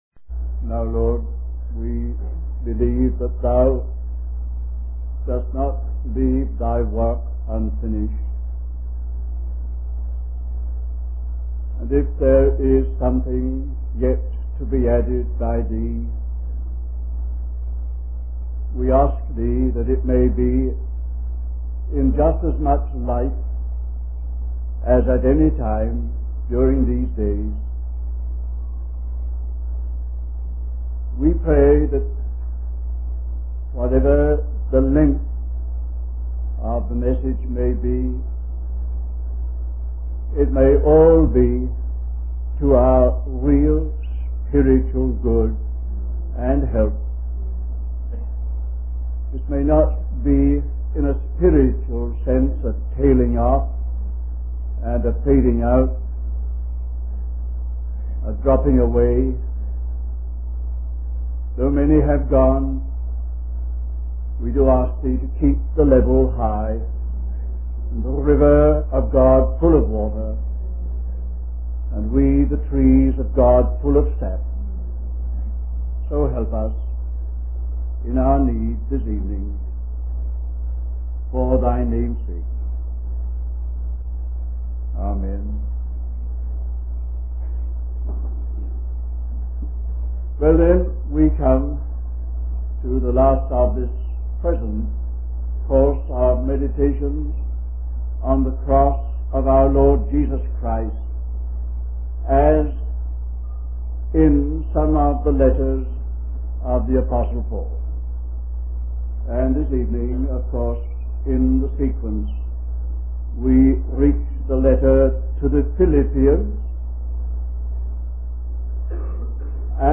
1967 Wabanna (Atlantic States Christian Convocation) Stream or download mp3 Summary The way of victory is the way of the cross.